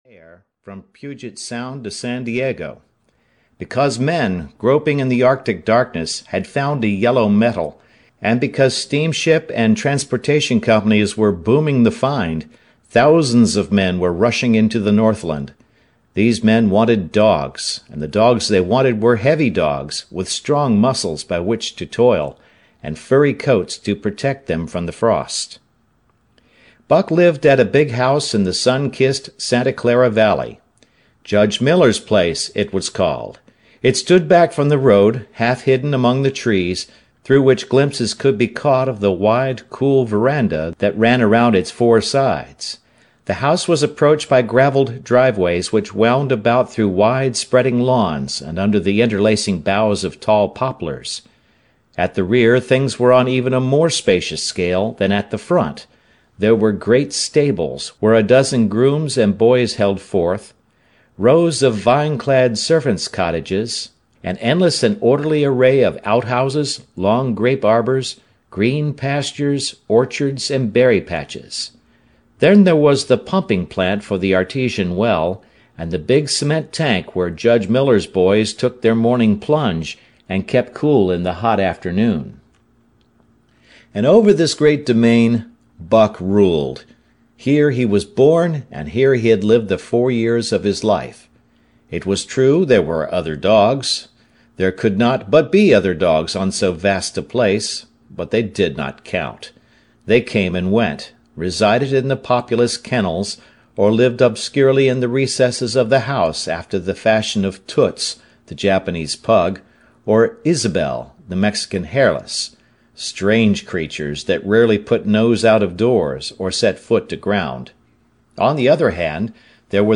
The Call of the Wild (EN) audiokniha
Ukázka z knihy